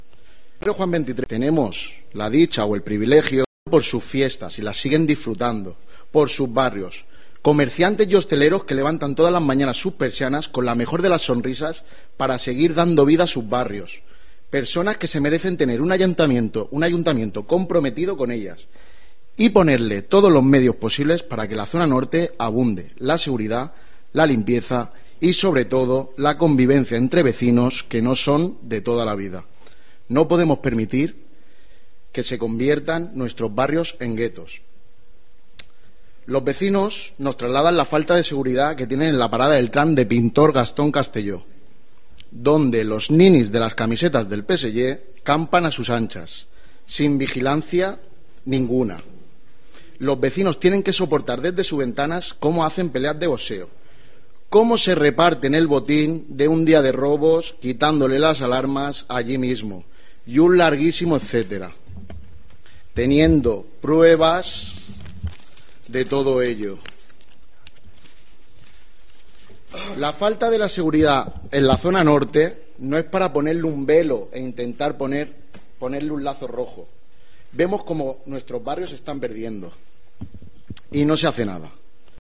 Esta mañana se ha producido en el Ayuntamiento de Alicante el pleno ordinario de septiembre en el que, entre diferentes cuestiones, se ha abordado la moción promovida por VOX sobre la seguridad en los barrios de la zona norte de Alicante. Mario Ortolà, miembro y concejal del partido conservador, ha trasladado la preocupación de los vecinos "de toda la vida" en materia de seguridad.